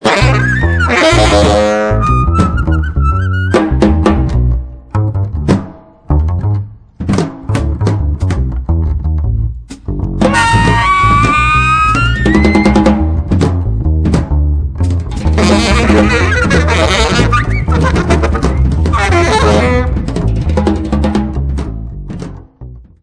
( Attention!! Free-Jazz!!)